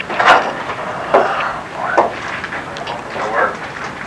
This EVP was also recorded at the Ben Lomond Hotel on a tour we gave. We were in the tunnels with the film crew for the T.V. show and you can here this voice say what sounds like "work", the first part is hard to make out. At the end of the clip you can hear me say that'll work.